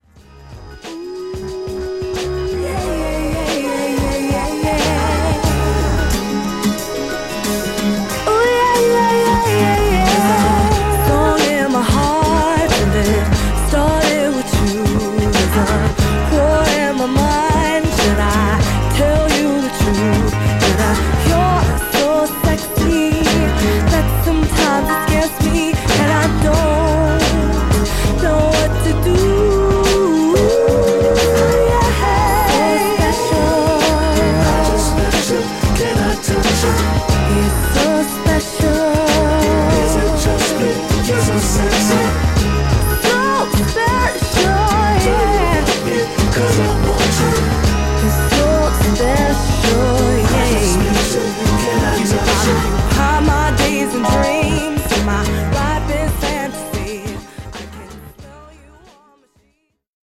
ホーム ｜ CROSSOVER / BREAKBEATS > CROSSOVER